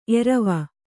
♪ erava